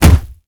punch_heavy_huge_distorted_01.wav